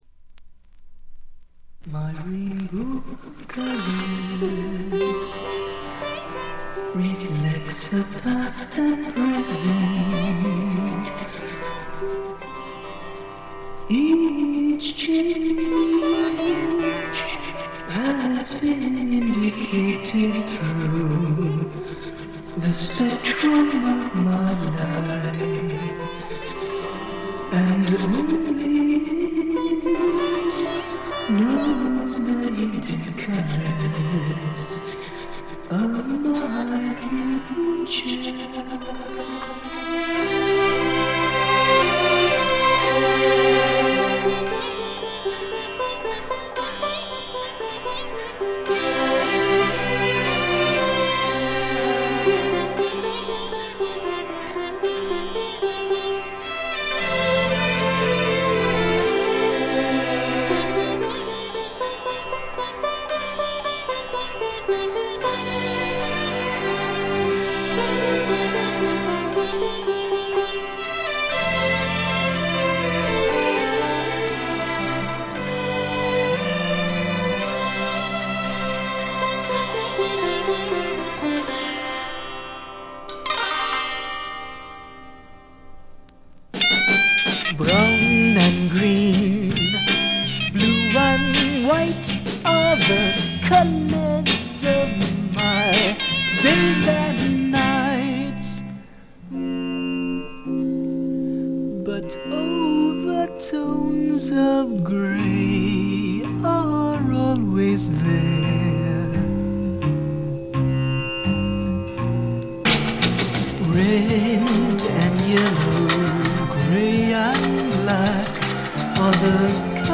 細かなバックグラウンドが出てはいますが静音部で分かる程度。気になるようなノイズはありません。
黒人臭さのまったくないヴォイスがまた素晴らしいです。
試聴曲は現品からの取り込み音源です。